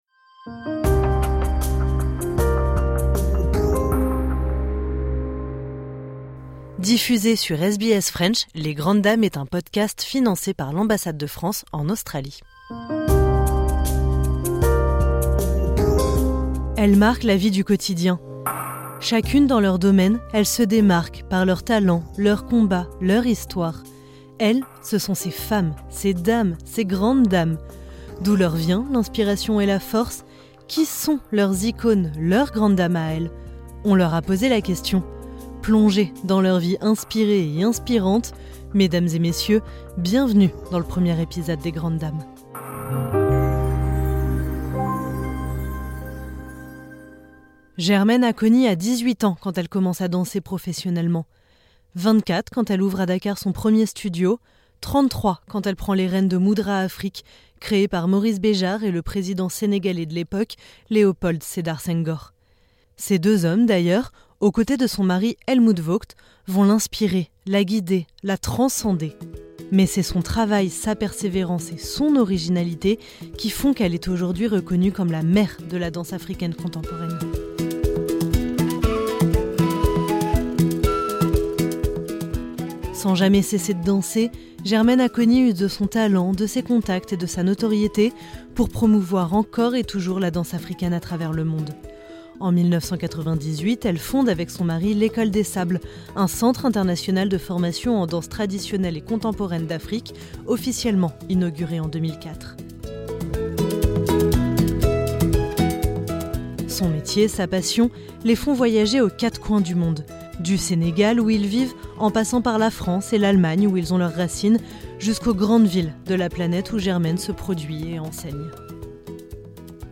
Elle nous parle de sa carrière, mais aussi de sa Grande Dame à elle, Joséphine Baker. Cette interview a été enregistrée dans les studios de Melbourne, en mars 2023.